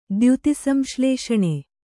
♪ dyuti samślēṣaṇe